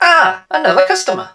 barley_hurt_04.wav